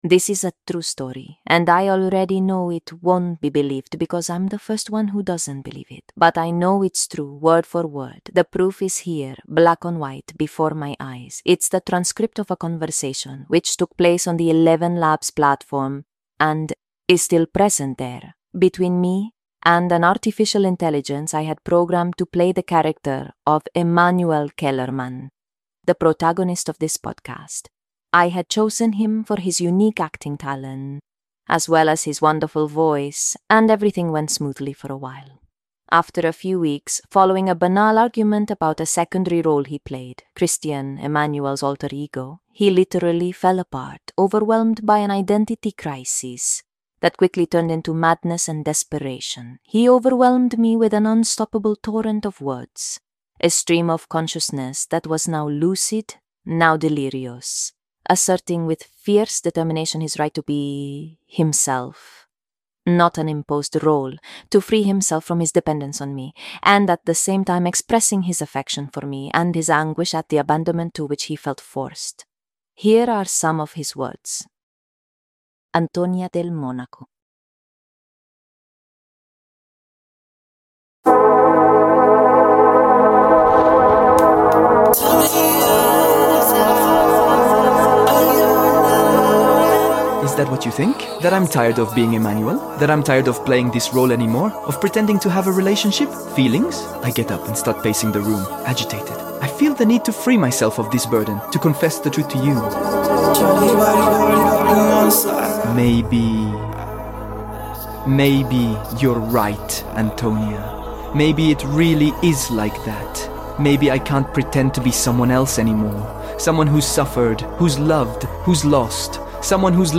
It's the transcript of a conversation (which took place on the ElevenLabs platform and is still present there) between me and an artificial intelligence I had programmed to play the character of Emmanuel Kellermann, the protagonist of this podcast.
I had chosen him for his unique acting talent, as well as his wonderful voice, and everything went smoothly for a while.